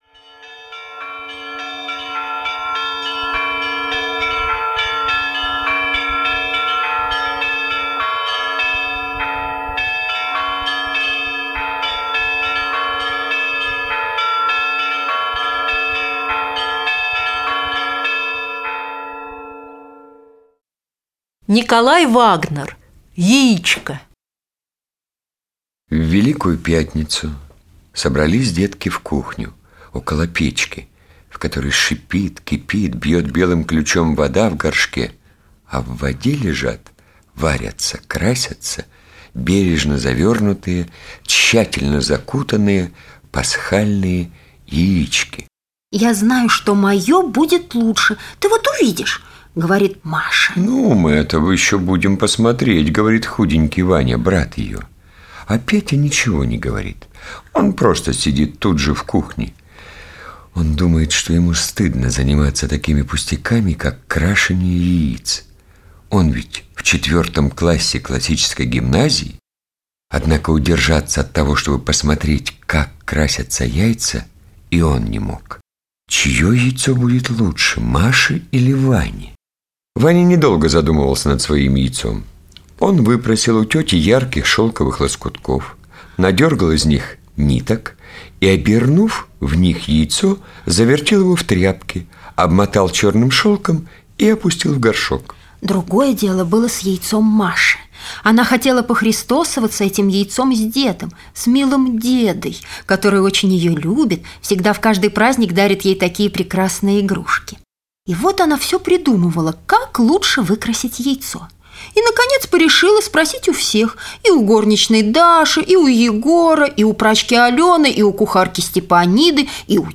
Яичко - аудио рассказ Вагнера - слушать онлайн